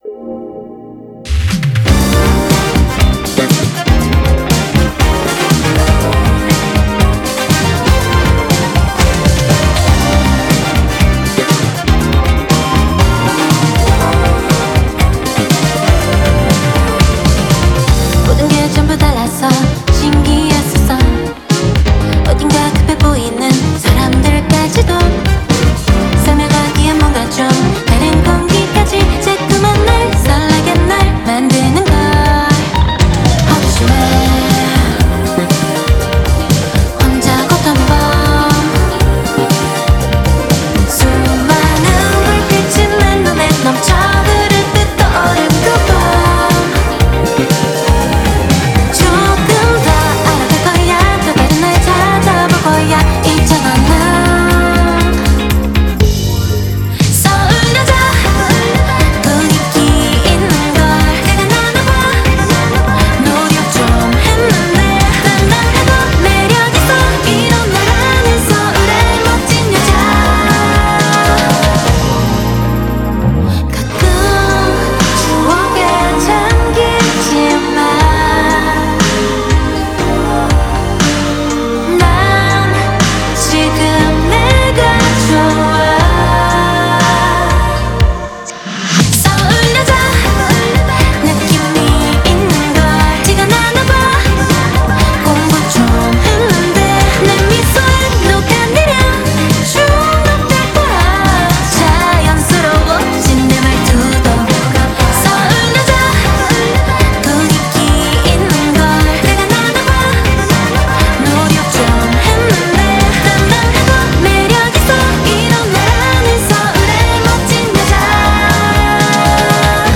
BPM120
Genuinely great city pop music.